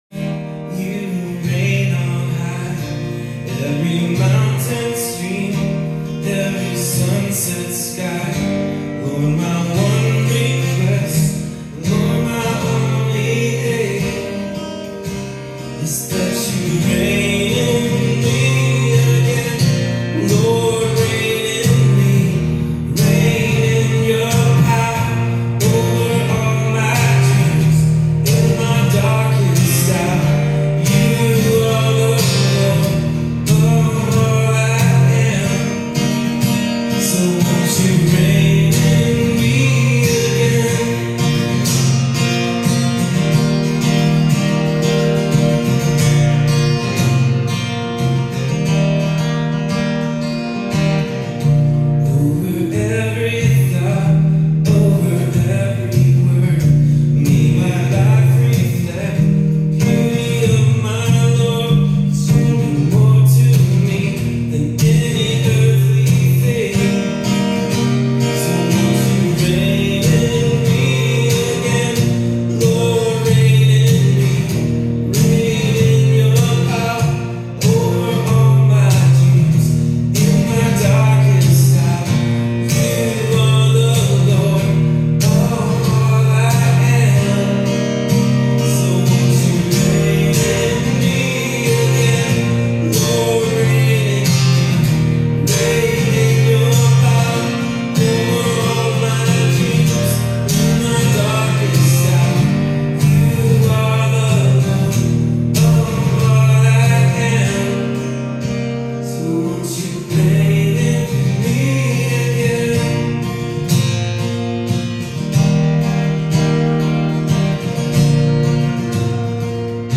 Click the play button below for a 3 minute live worship audio.
hilltop_live_worship_jan3-2026.mp3